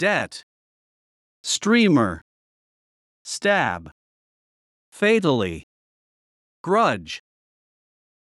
音声を再生し、強勢のある母音（＝大きな赤文字）を意識しながら次の手順で練習しましょう。
debt /dɛt/（名）借金、負債
grudge /ɡrʌdʒ/（名）恨み、遺恨；（動）しぶしぶ与える